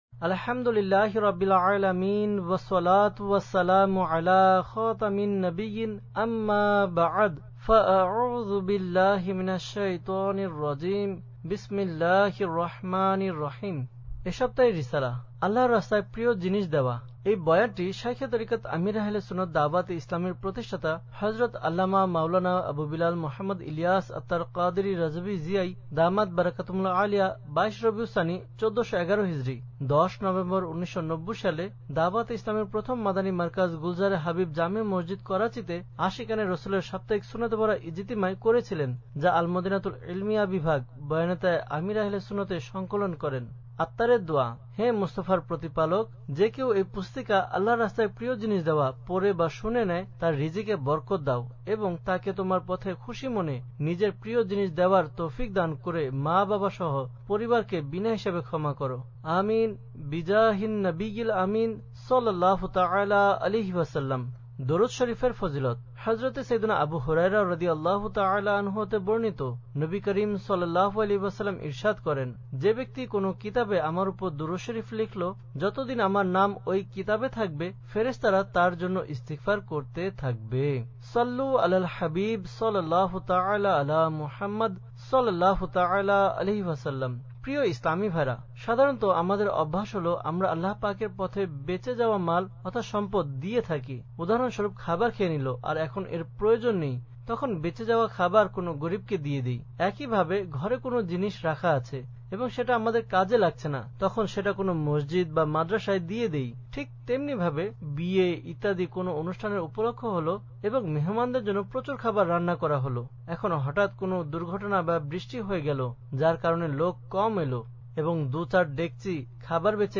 Audiobook - আল্লাহর রাস্তায় প্রিয় জিনিস দেওয়া (Bangla)